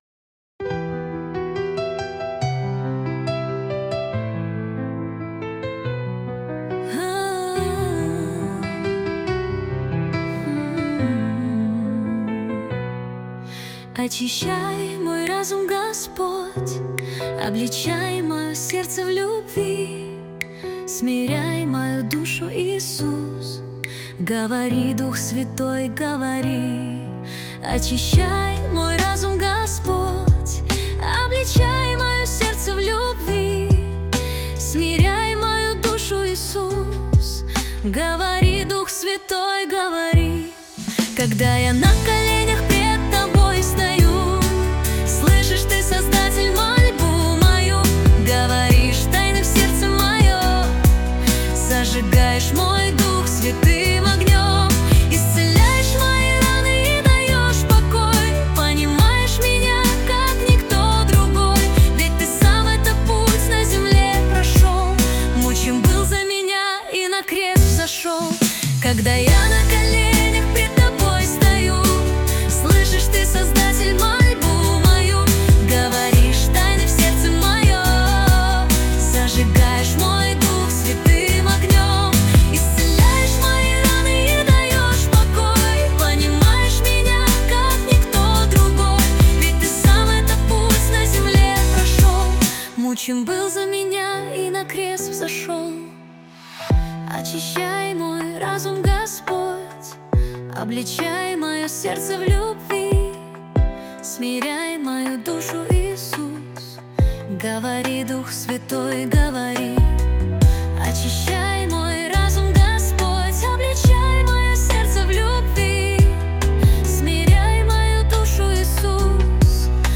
378 просмотров 1332 прослушивания 144 скачивания BPM: 70